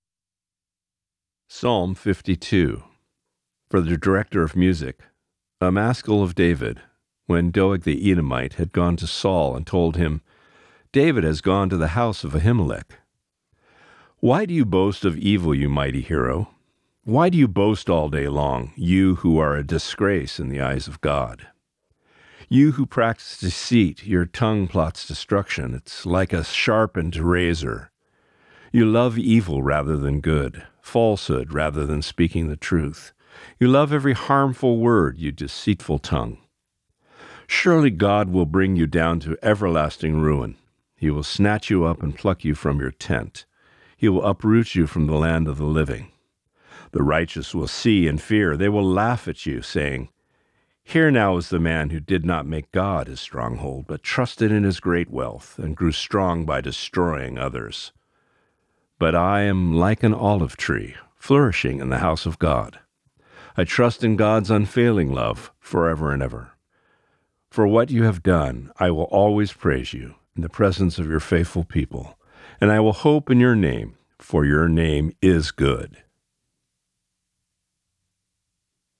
Reading: Psalm 52